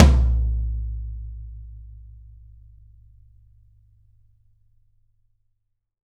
YAM18 ROOM-L.wav